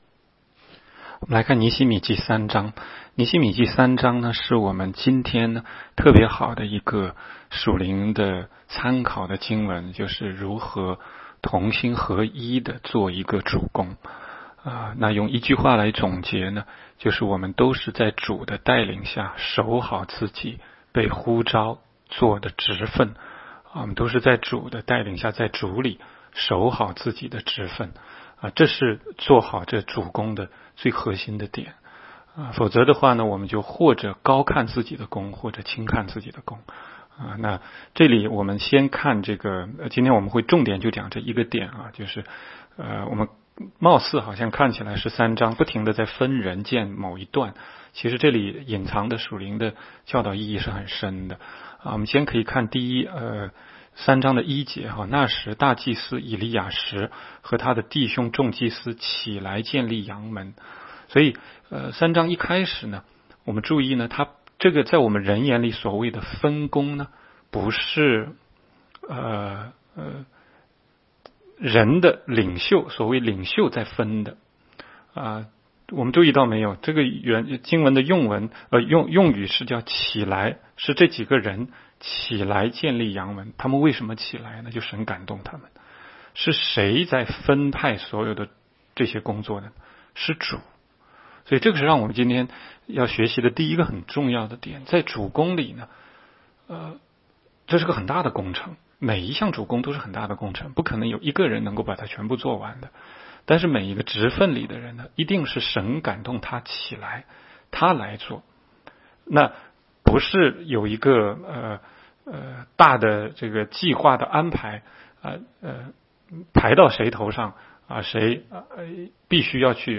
16街讲道录音 - 每日读经-《尼希米记》3章